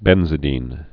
(bĕnzĭ-dēn)